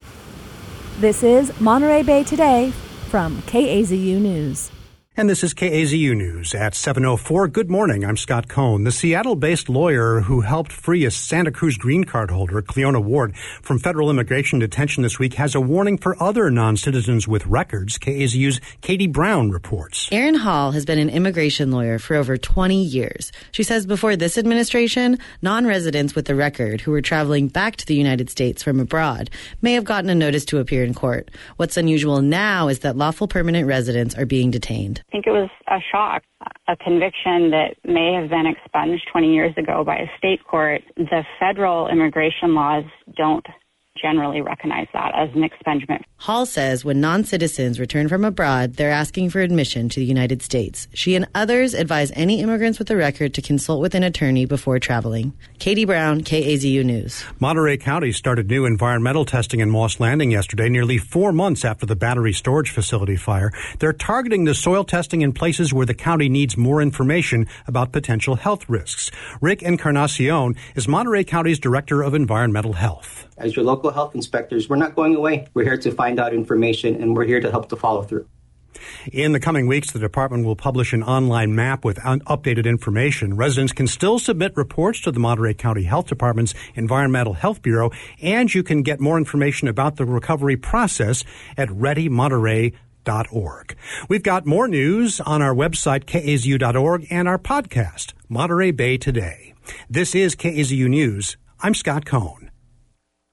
In today's newscast, an immigration lawyer who helped a Santa Cruz woman return home after being detained cautions others about travel.